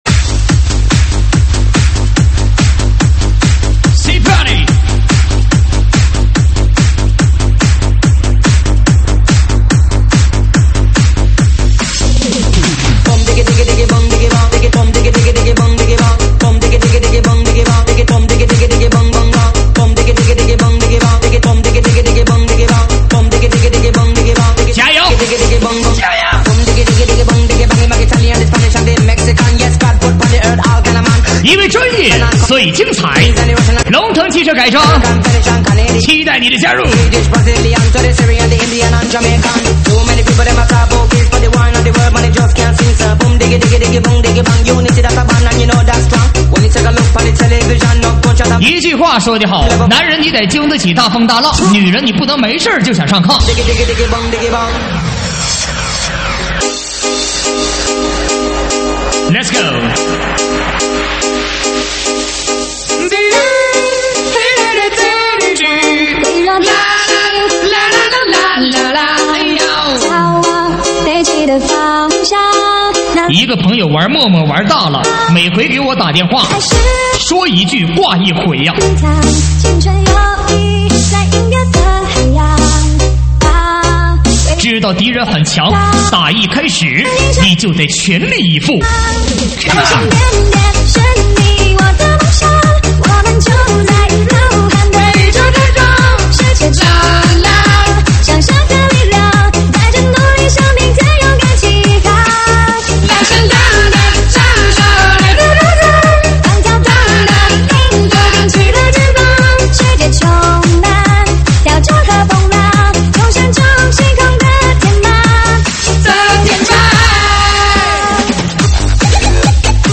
舞曲类别：喊麦现场